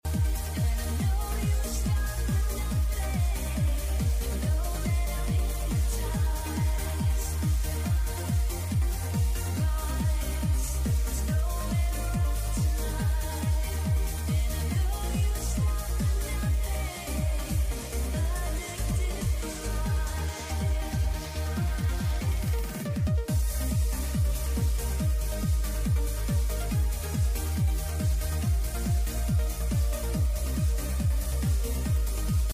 DJ LIVE STREAMING